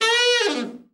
ALT FALL   8.wav